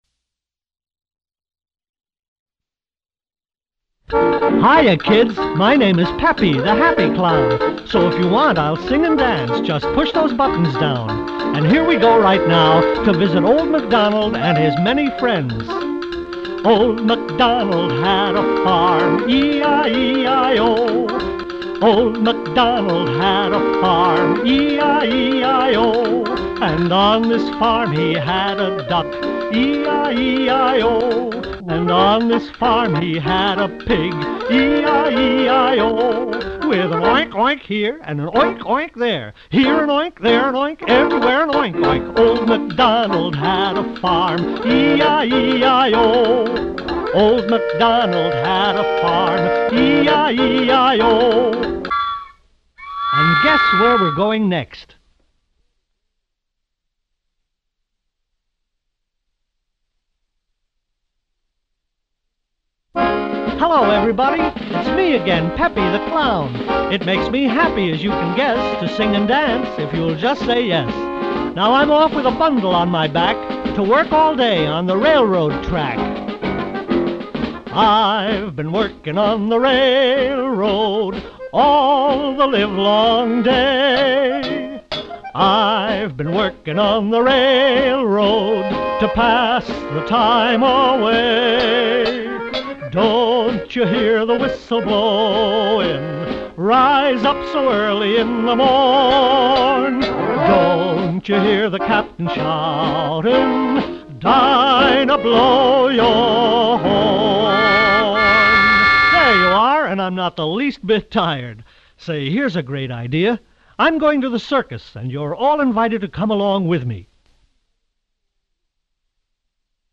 talked and sang
calliope music